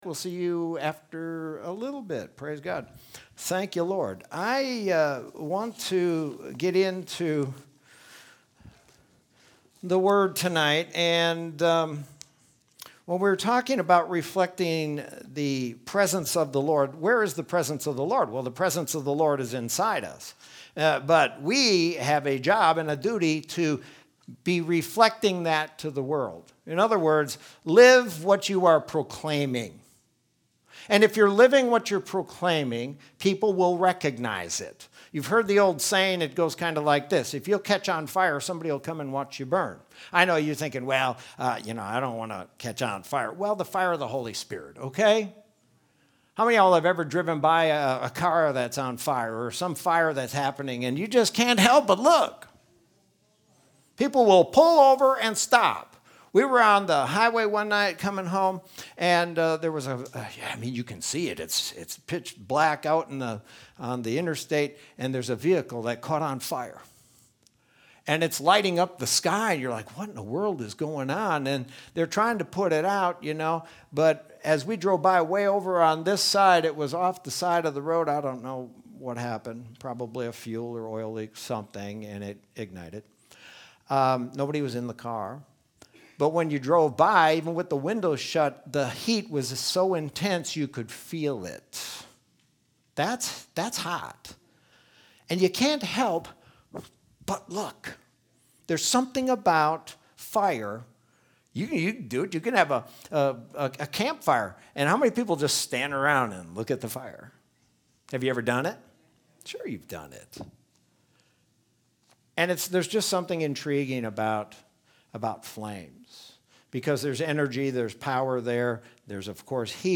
Sermon from Wednesday, September 2nd, 2020.